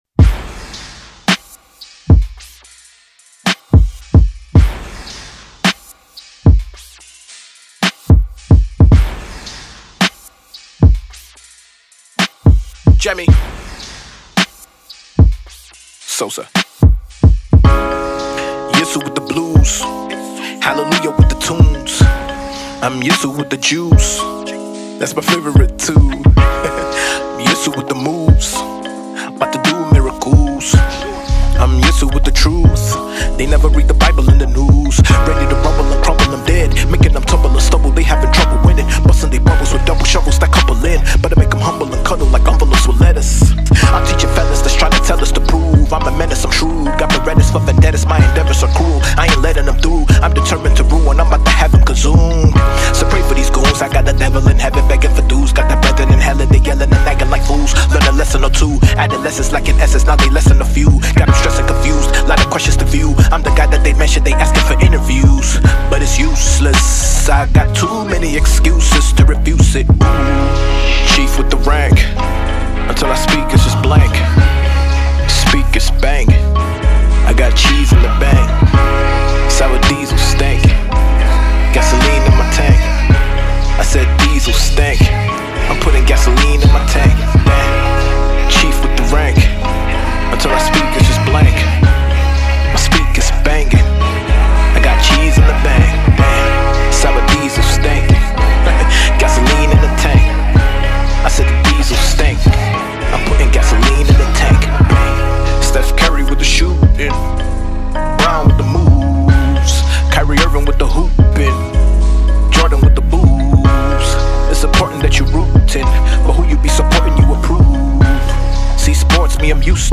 Ghanaian rapper and lyrical beast